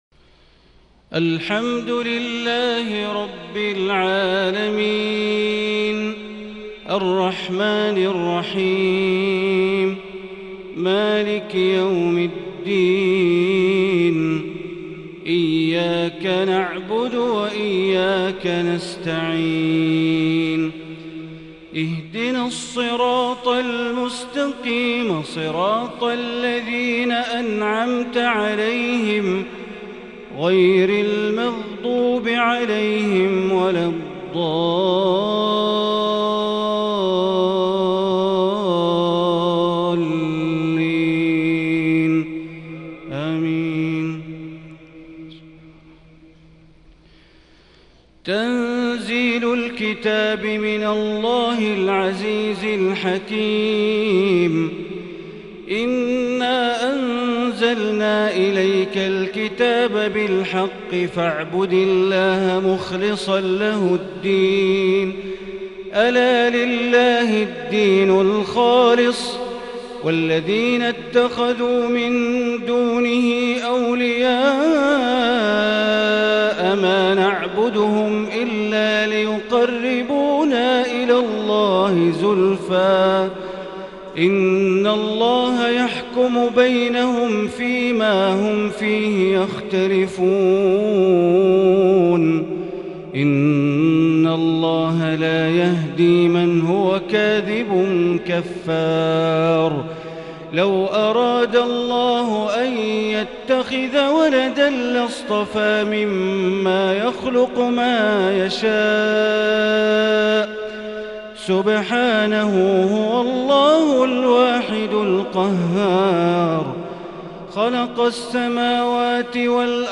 تهجد ليلة 30 رمضان 1441هـ سورة الزمر كاملة | Tahajjud 30st night Ramadan 1441H Surah Az-Zumar > تراويح الحرم المكي عام 1441 🕋 > التراويح - تلاوات الحرمين